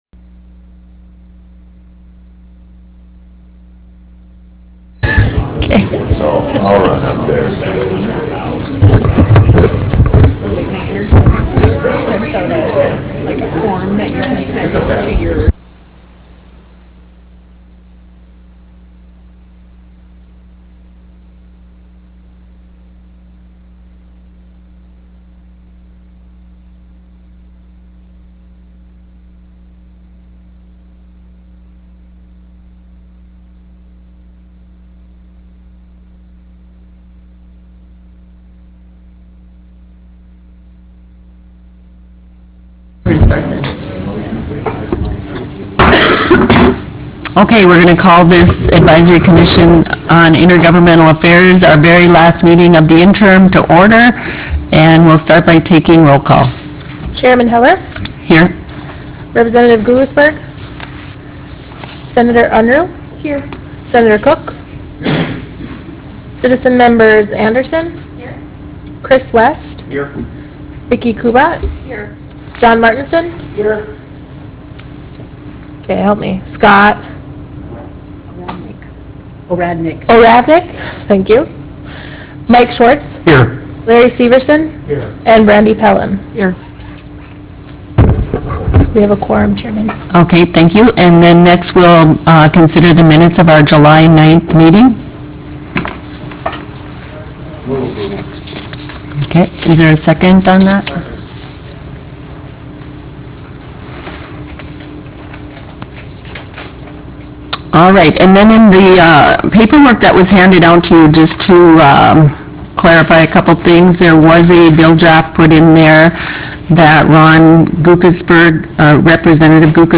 Roughrider Room State Capitol Bismarck, ND United States
Meeting Audio